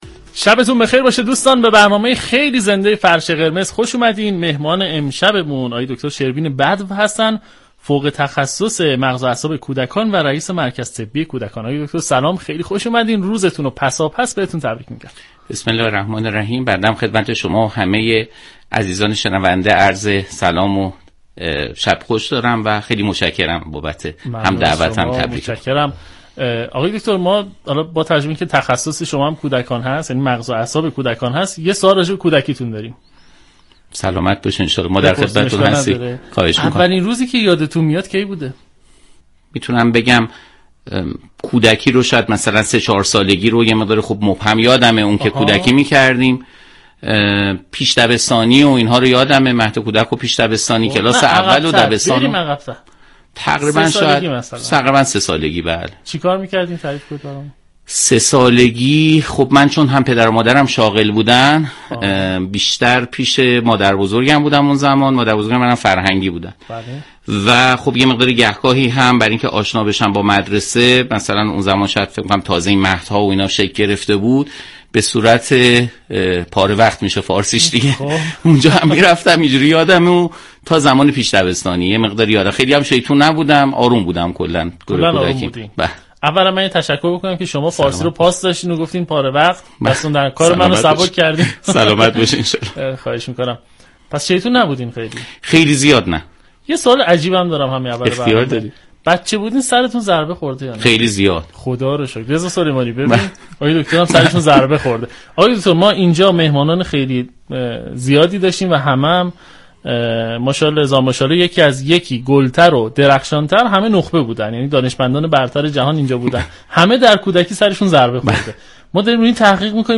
به گزارش روابط عمومی رادیو صبا، «فرش قرمز» عنوان یكی از برنامه‌های گفتگو محور این شبكه رادیویی است كه جمعه شب‌ها میزبان نخبگان خوب كشور ما می‌شود.